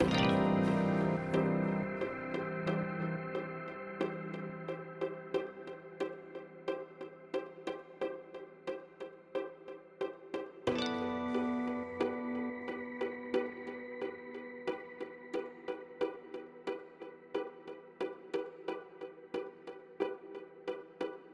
90 bouncing strings
8bars 90 90bpm atmospheric bounce bouncing bpm chord sound effect free sound royalty free Sound Effects